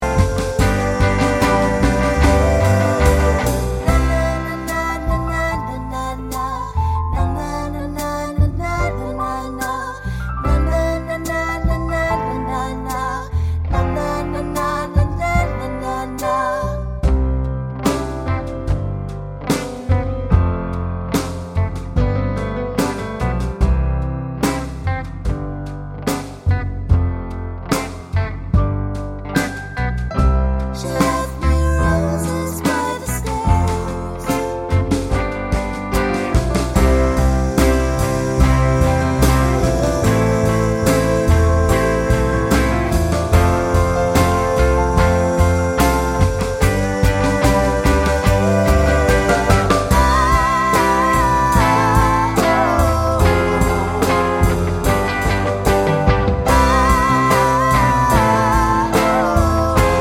no Backing Vocals Pop (2020s) 3:21 Buy £1.50